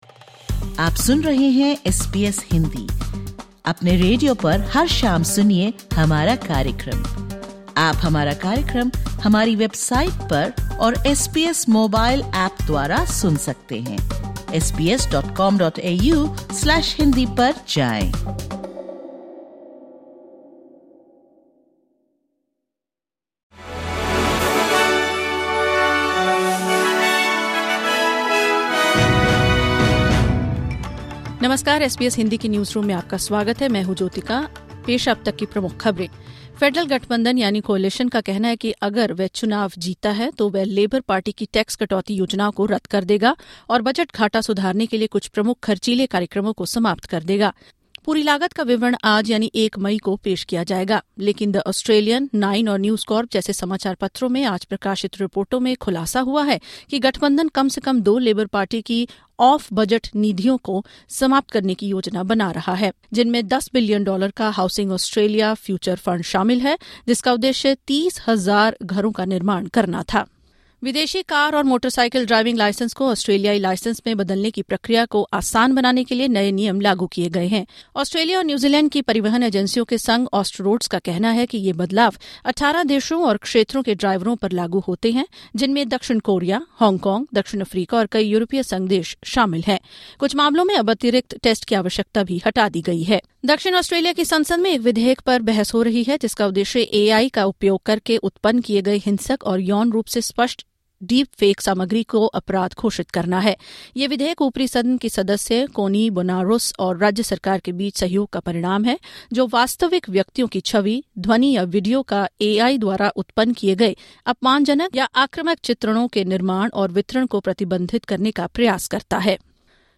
Listen to the top News of 01/05/2025 from Australia in Hindi.